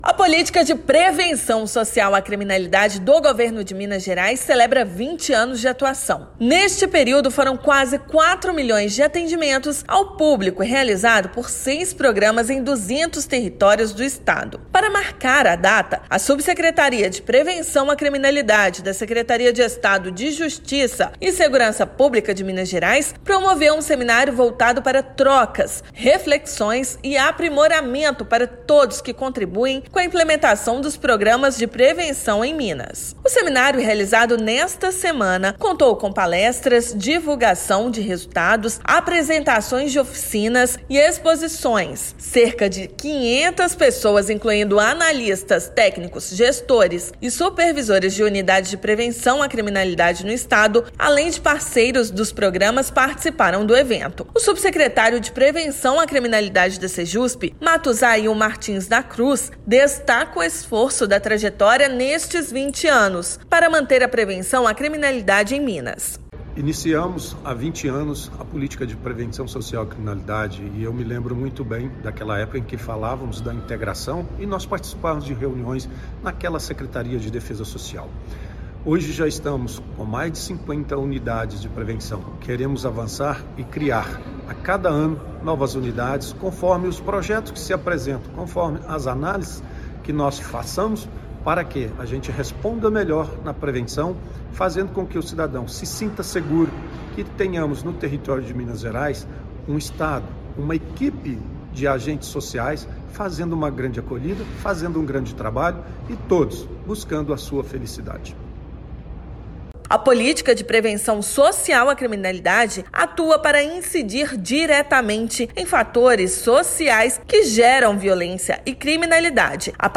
Programas somam quase 4 milhões de atendimentos ao público em duas décadas; seminário celebra trabalho que é referência para o Brasil e para o mundo. Ouça matéria de rádio.